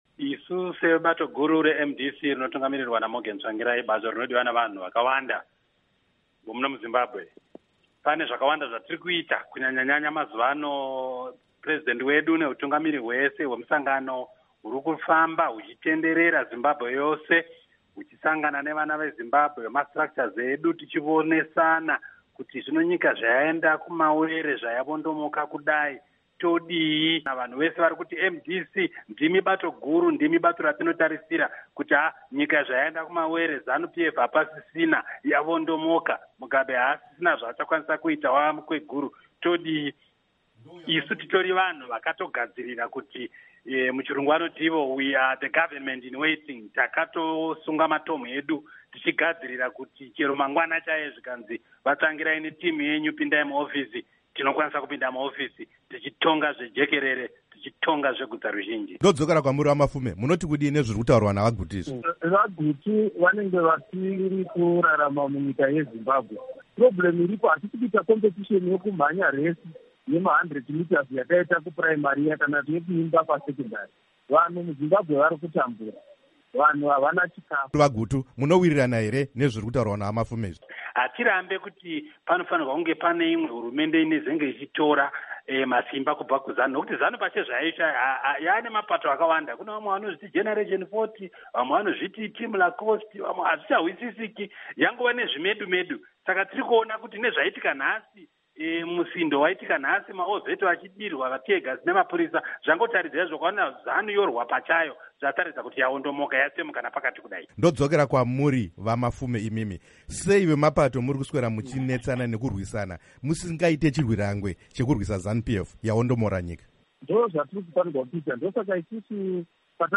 Hurukuro naVa Obert Gutu naVaJacob Mafume